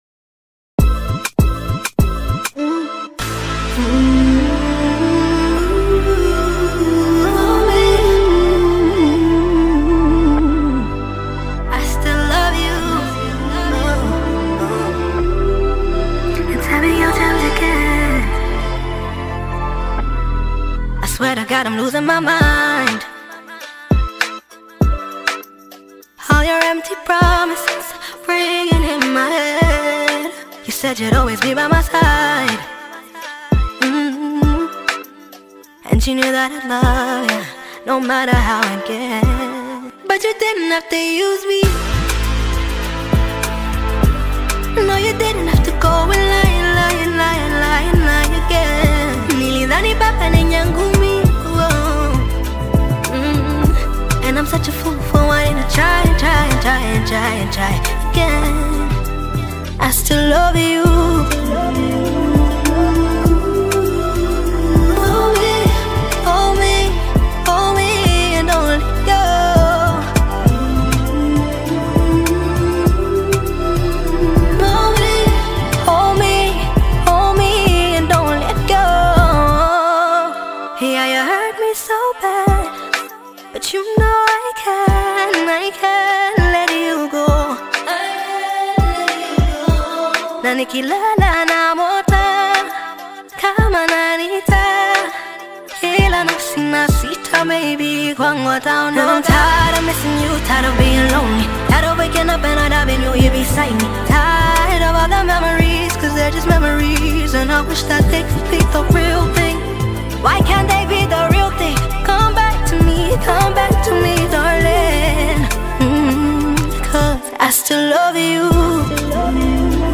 a talented female singer and songwriter
The production includes rich harmonies and modern rhythms